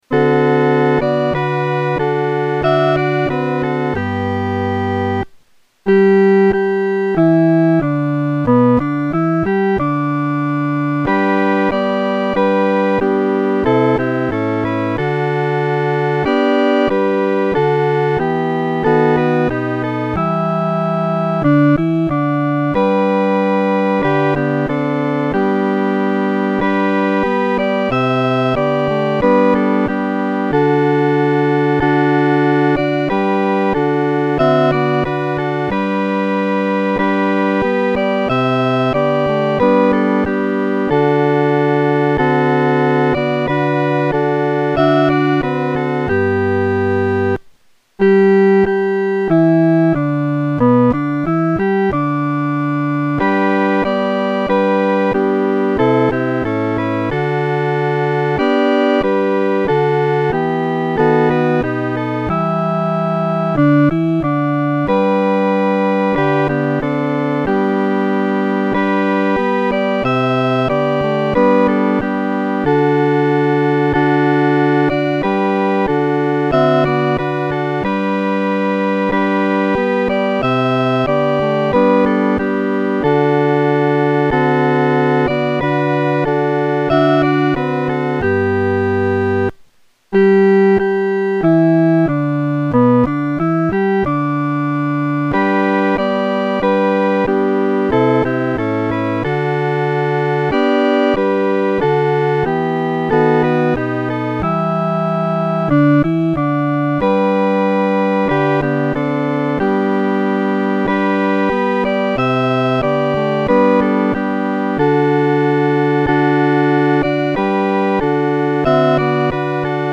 合唱
四声 下载
这是他第一次为赞美诗谱曲，写的曲调有民间风味，且简单易唱，富有活力。